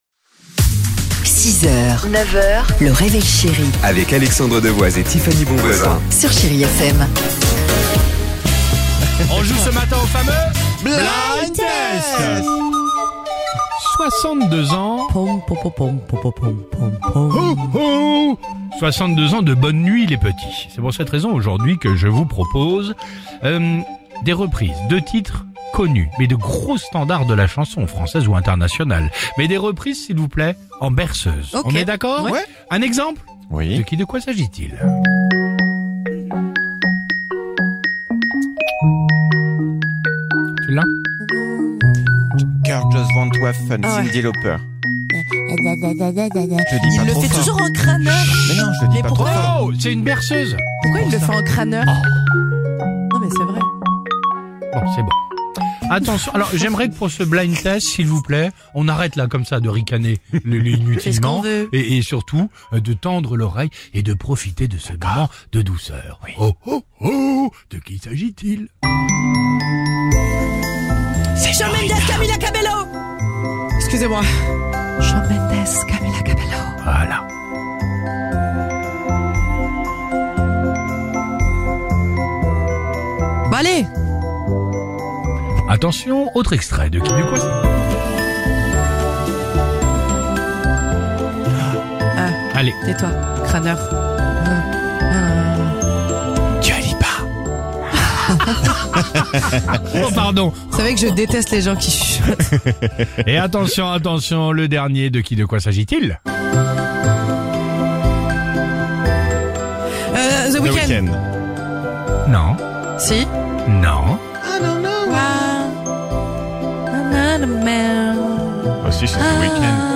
Blind Test - Reprises en version berceuse !